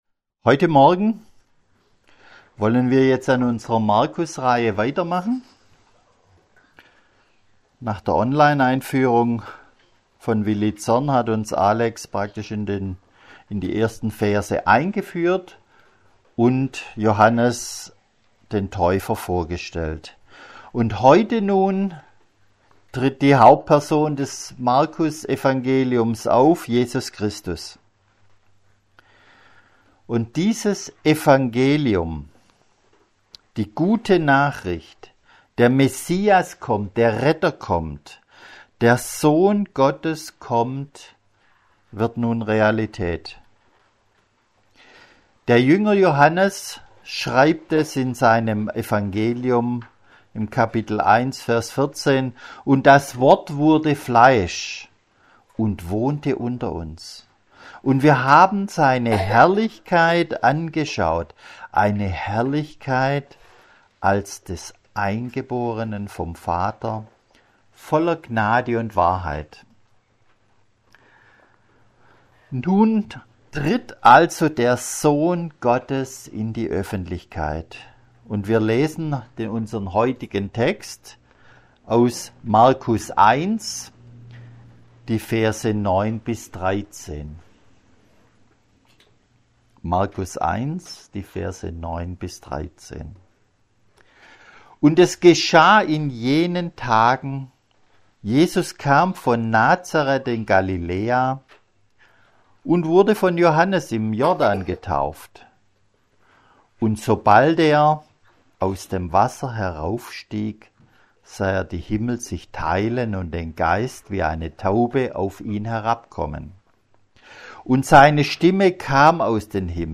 Predigtreihe: Markusevangelium